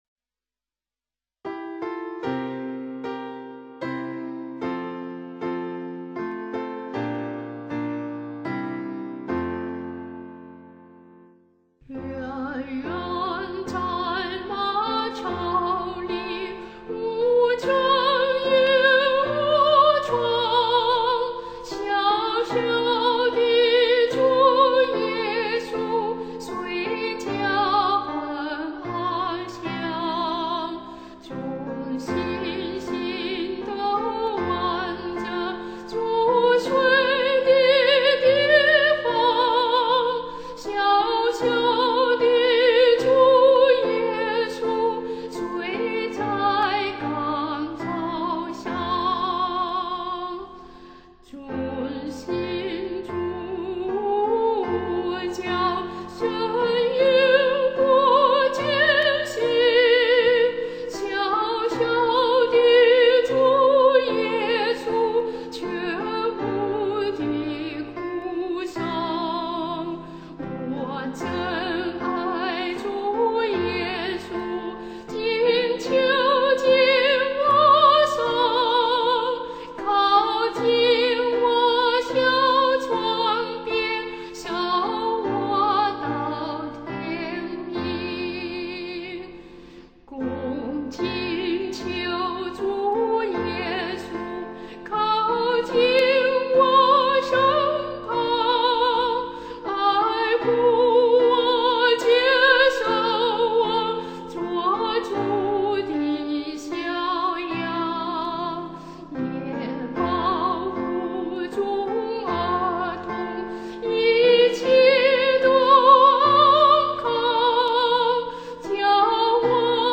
伴奏
简洁的节奏宛如轻轻摇着婴儿的摇篮声。